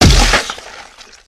gore4.ogg